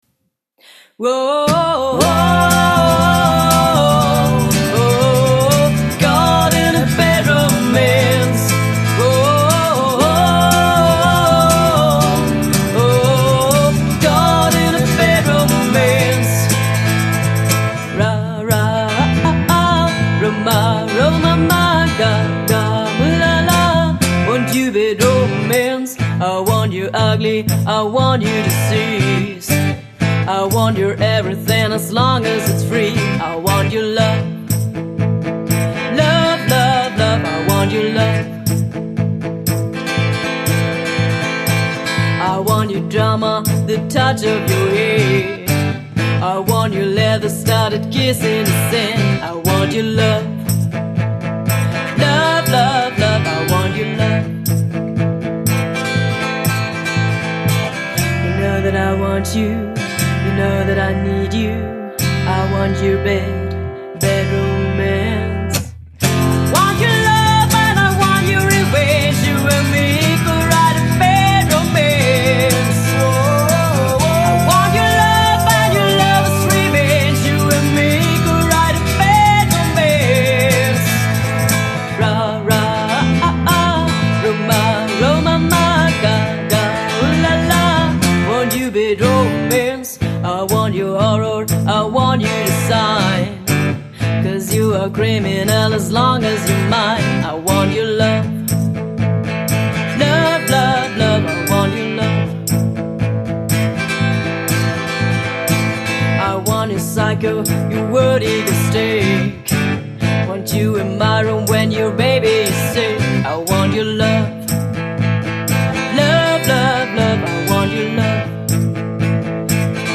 Cover-Trio aus Linz
(Cover-Genres: Pop/Classic-Rock/Oldies/Austro-/Deutsch-Pop)